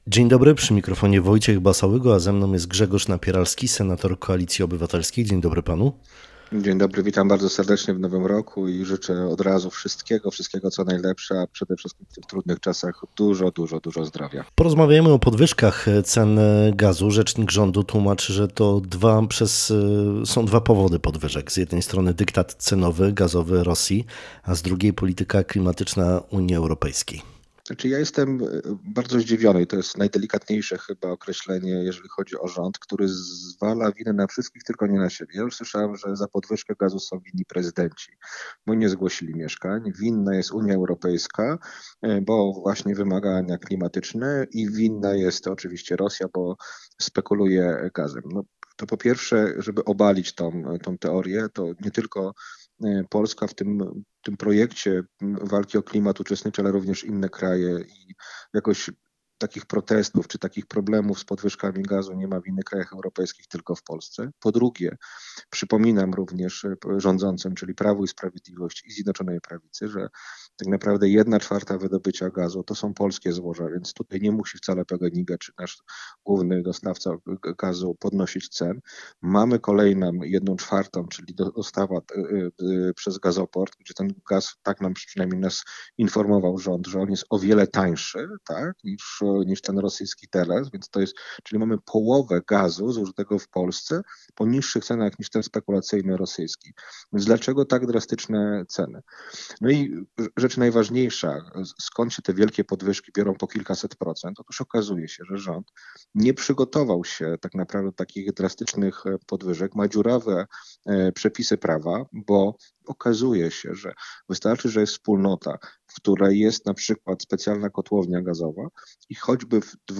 Dzisiejsza Rozmowa Dnia z naszym gościem, Grzegorzem Napieralskim, który jest Senatorem Koalicji Obywatelskiej poruszyła wiele tematów, które obecnie mają największe zainteresowanie. Chodzi głównie o podwyżkę cen gazu, co jest tematem mocno kontrowersyjnym.